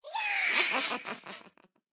Minecraft Version Minecraft Version snapshot Latest Release | Latest Snapshot snapshot / assets / minecraft / sounds / mob / vex / charge1.ogg Compare With Compare With Latest Release | Latest Snapshot
charge1.ogg